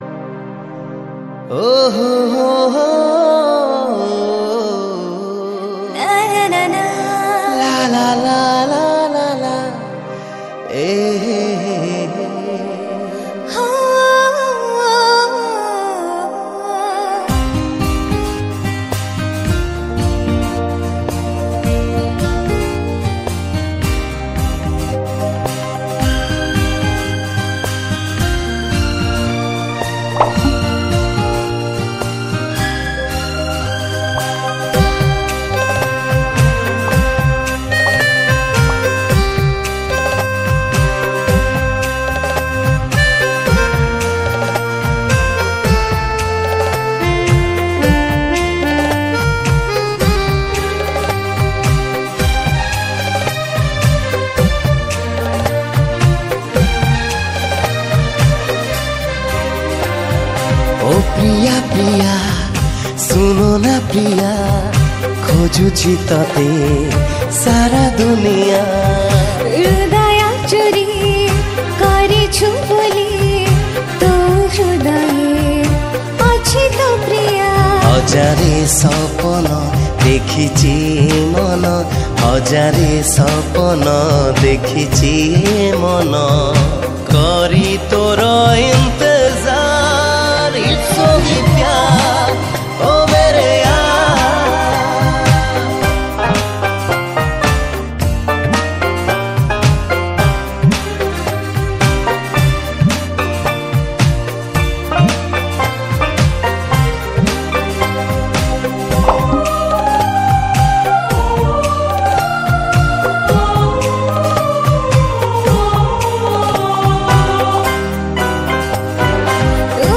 New Odia Romantic Song